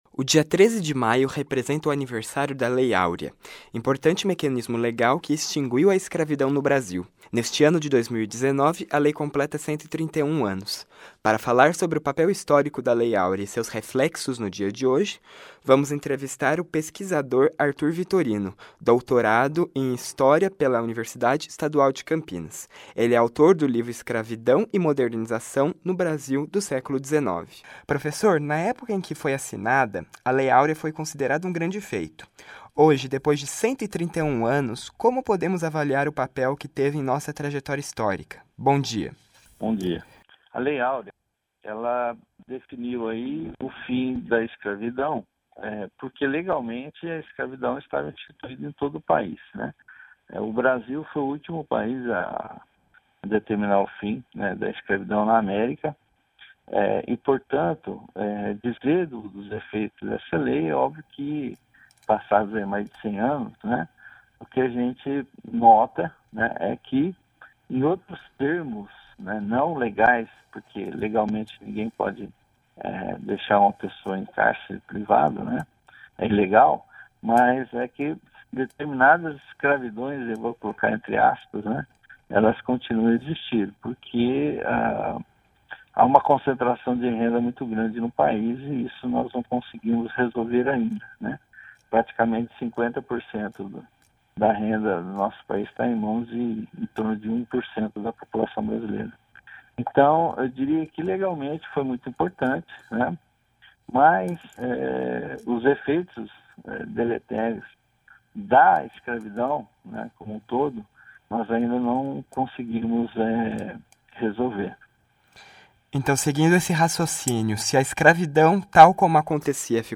Entrevista-híbrida-sobre-a-Lei-Áurea.mp3